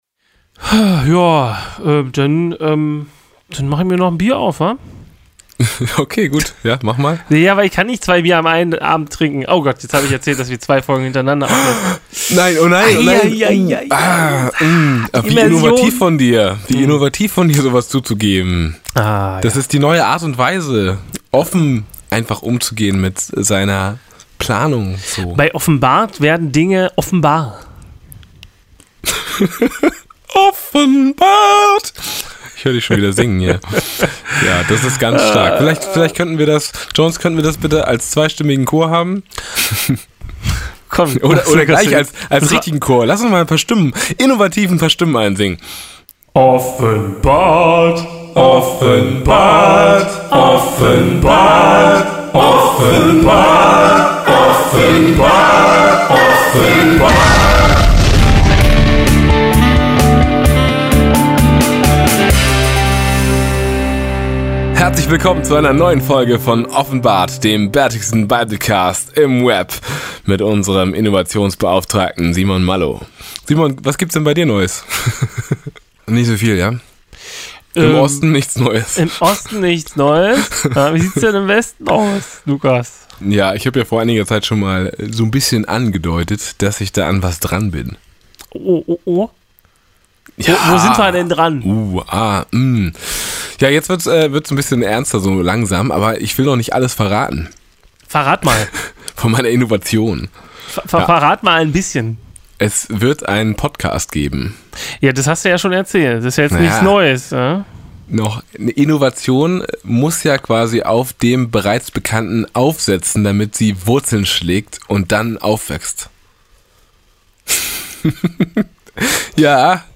Diese Woche grüßen wir euch fröhlich mit dem Offenbart-Chor! Sodann taucht ein auffälliger Typ auf und hilft zwei Boys, einen Festsaal für den Meister klar zu machen.